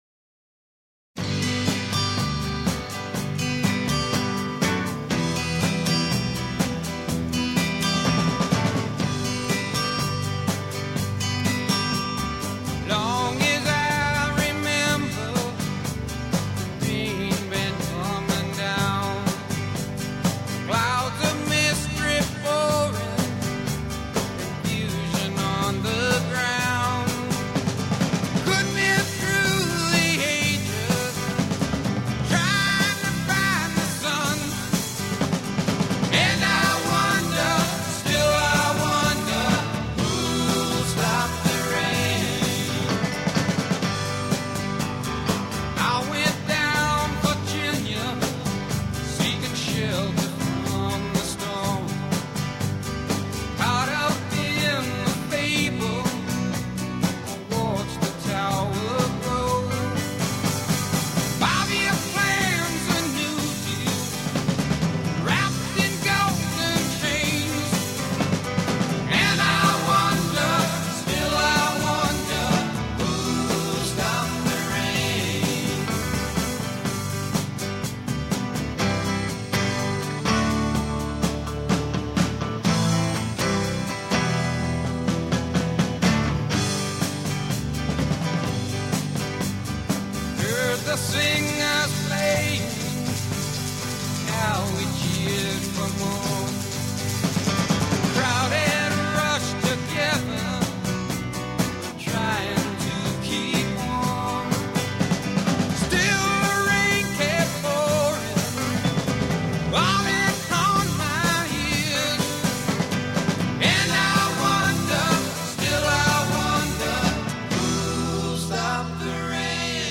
Рок музыка Рок Rock